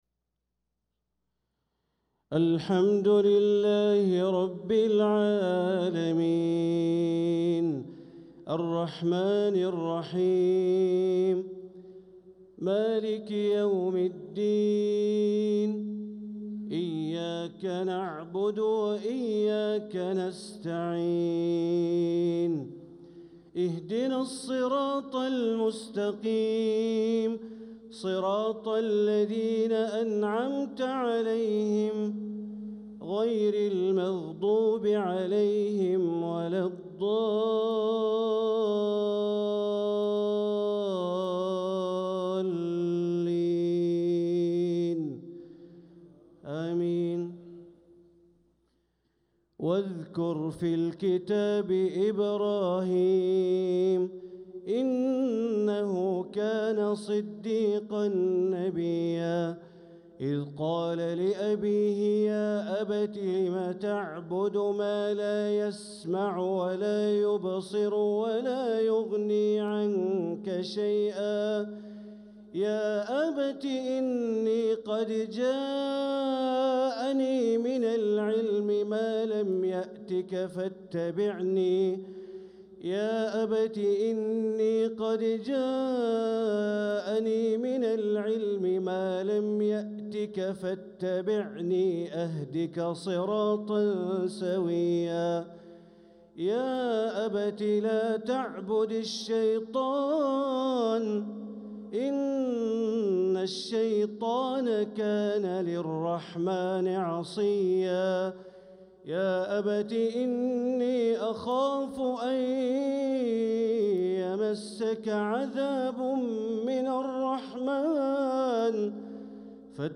صلاة العشاء للقارئ بندر بليلة 27 ربيع الأول 1446 هـ
تِلَاوَات الْحَرَمَيْن .